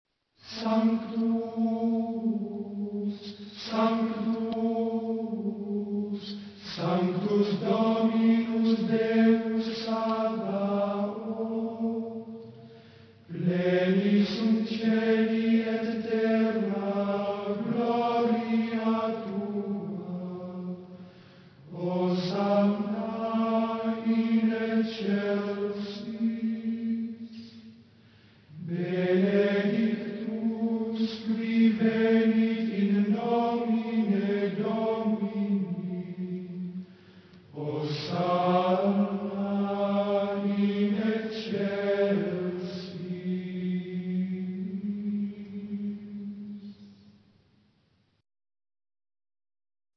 Gregoriaans
hebben overwegend slechts één noot op elke lettergreep.
Sanctus Syllabisch